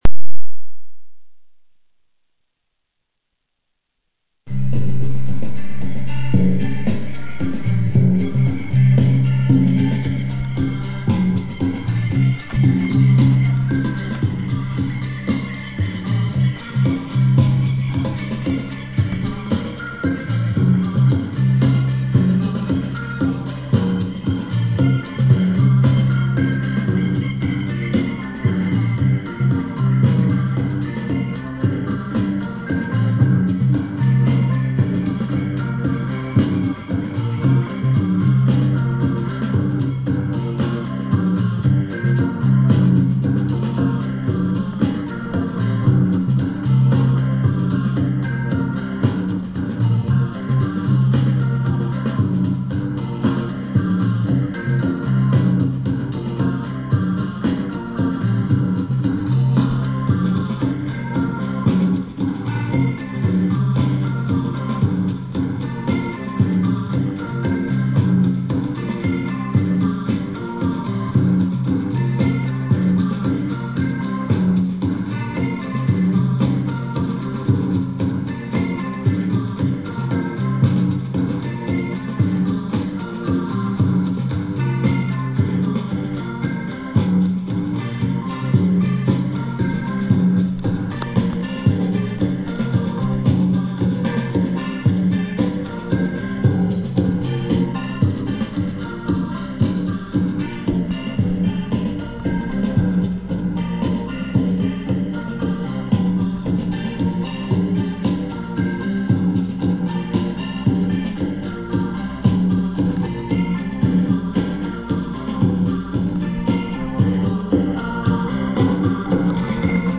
Piano e tastiere
Batteria
contrabbasso
Minimal – Jazz